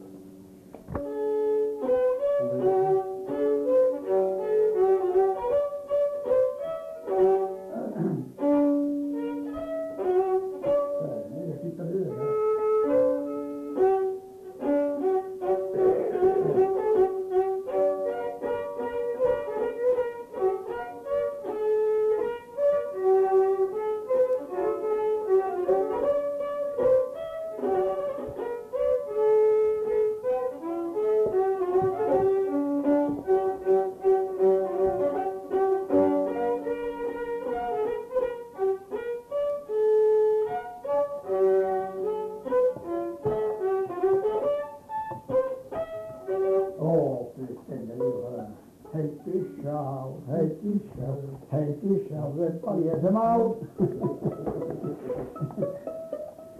Lieu : Saint-Michel-de-Castelnau
Genre : morceau instrumental
Instrument de musique : violon
Danse : scottish
Ecouter-voir : archives sonores en ligne